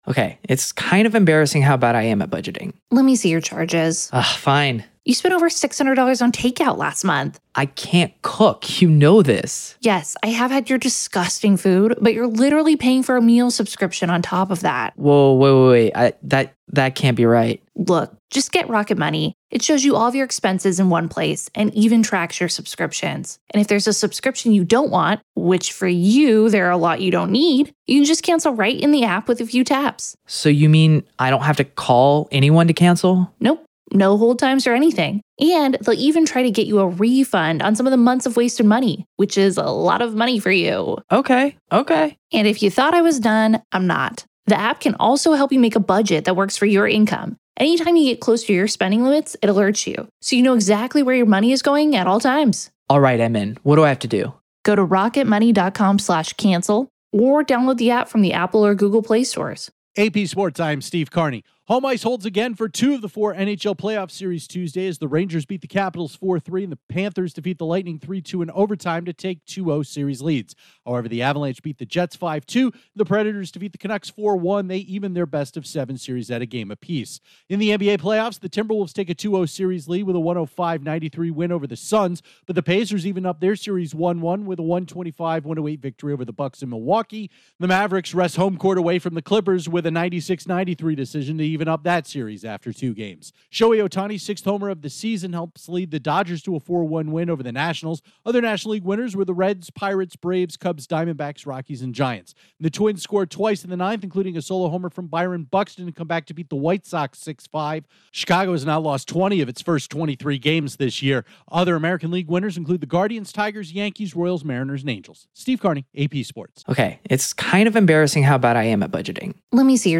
Facebook Twitter Headliner Embed Embed Code See more options The Rangers and Panthers take two-game leads in their Stanley Cup playoffs series while the Avalanche and Predators even up their best-of-seven series, the Timberwolves take another game from the Suns, the Pacers and Mavericks each get their first win of the postseason, Shohei Ohtani's late homer helps the Dodgers beat the Nationals, and the Twins use a ninth-inning rally to beat the White Sox. Correspondent